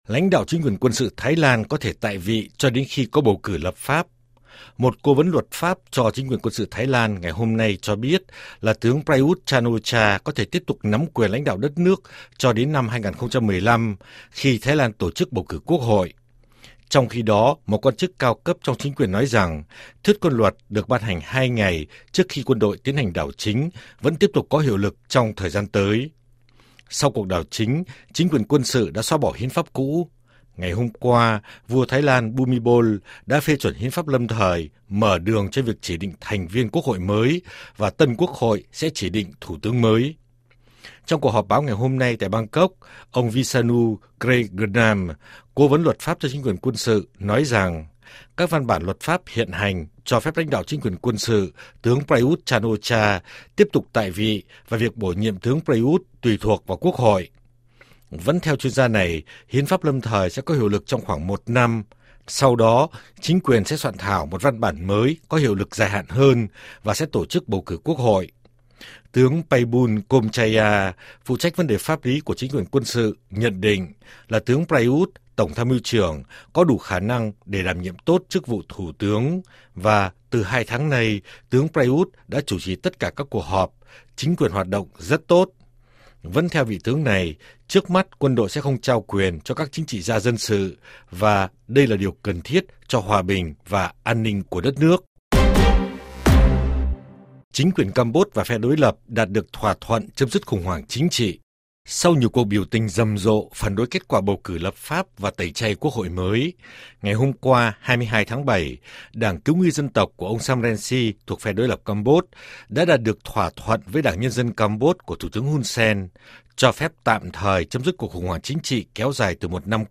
TAGS: CHÂU Á - CAM BỐT - PHỎNG VẤN - ĐỐI LẬP - CHÍNH TRỊ - BẦU CỬ - KHỦNG HOẢNG - THEO DÒNG THỜI SỰ